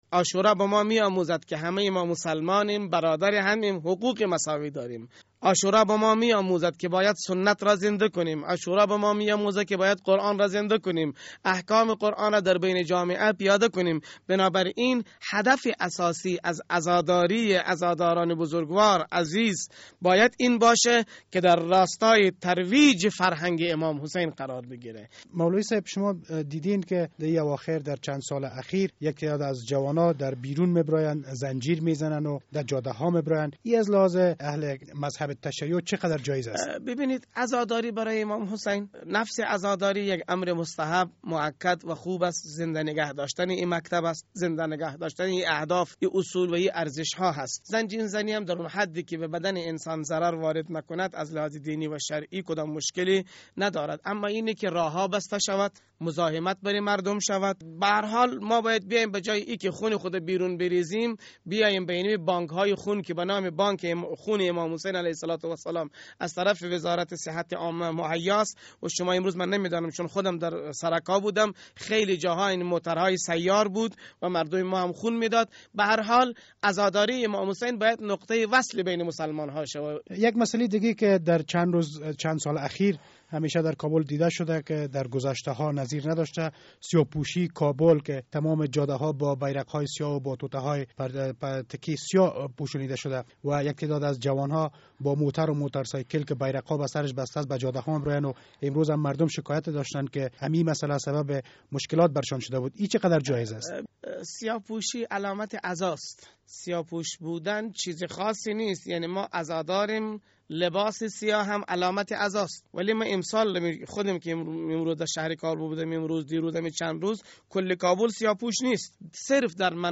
مصاحبه: از دهم محرم چگونه باید تجلیل به عمل آید